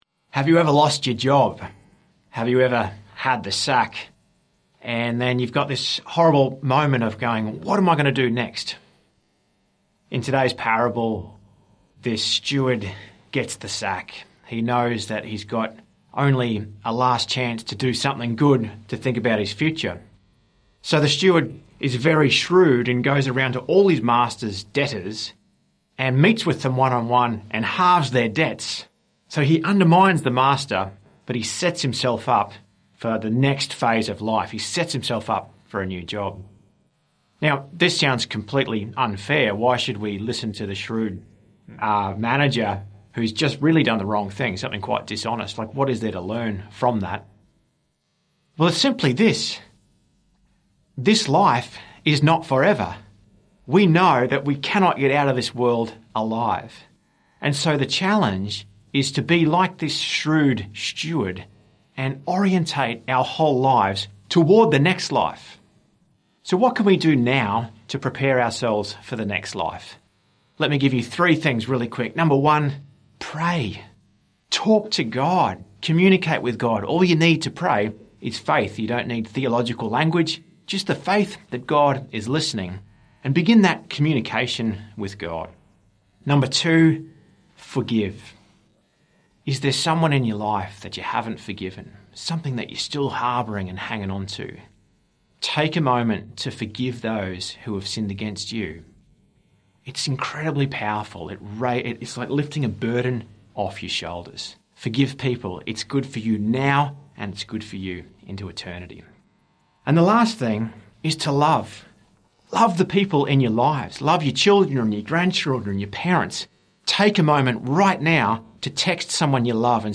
Twenty-Fifth Sunday in Ordinary Time - Two-Minute Homily